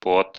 Ääntäminen
Synonyymit transpiration Ääntäminen France: IPA: /sɥœʁ/ Haettu sana löytyi näillä lähdekielillä: ranska Käännös Ääninäyte Substantiivit 1. пот {m} (pot) Muut/tuntemattomat 2. испа́рина {f} (ispárina) Suku: f .